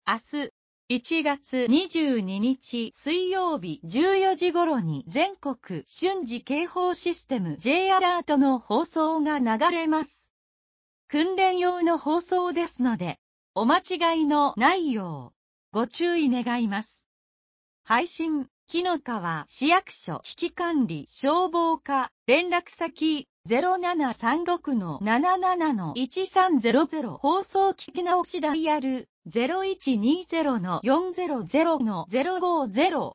明日、１月２２日（水）１４時頃に全国瞬時警報システム（Ｊアラート）の放送が流れます。 訓練用の放送ですので、お間違いのないよう、ご注意願います。